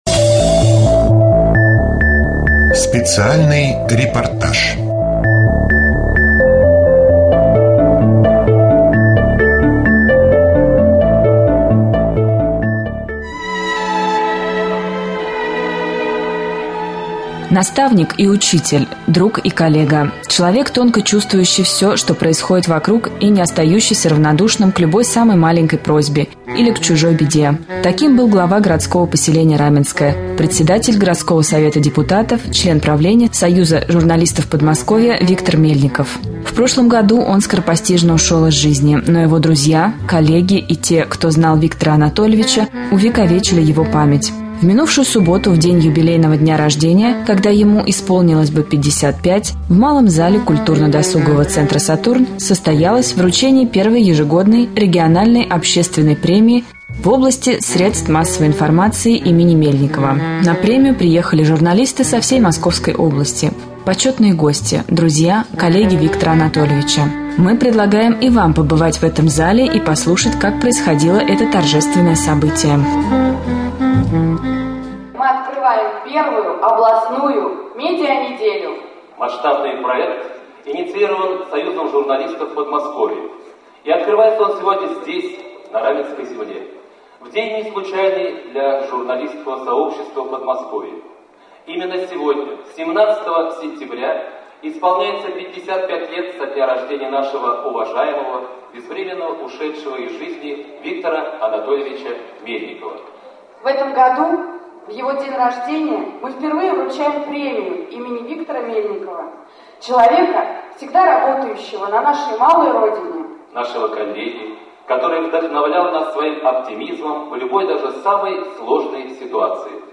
Рубрика «Специальный репортаж». В КДЦ «Сатурн» состоялась первая церемония вручения премии СМИ московской области имени В.А. Мельникова.